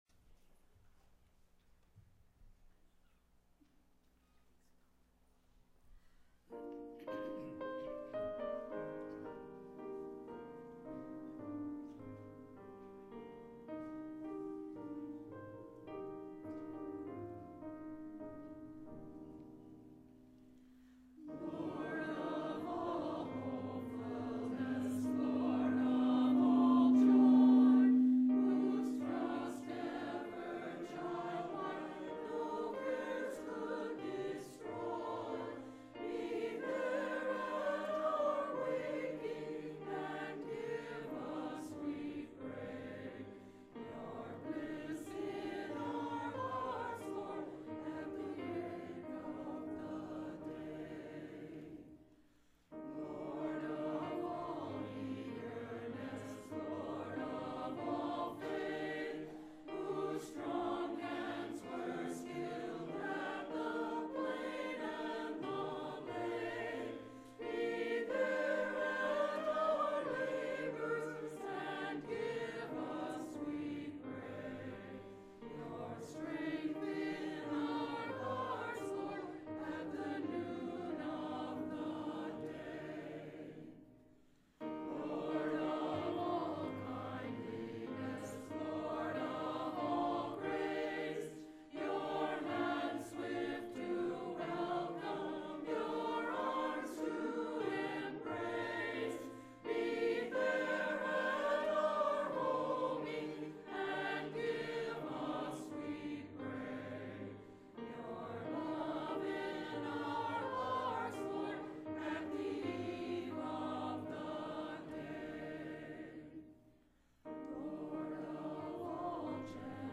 Posted in Sermons on 03.